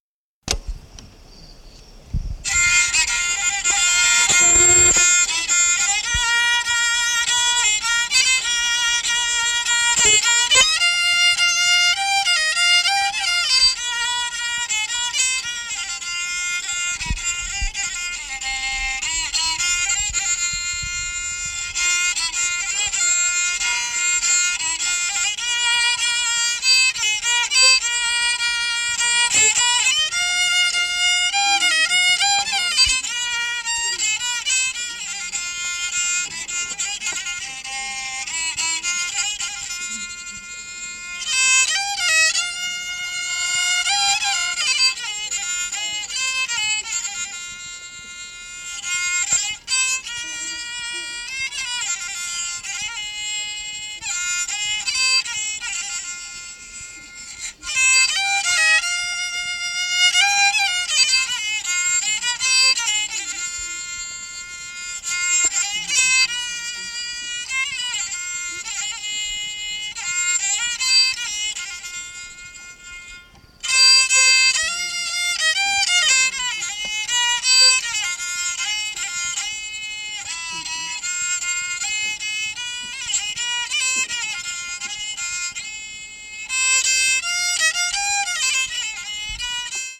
1953年から72年の間に行ったフィールド録音集！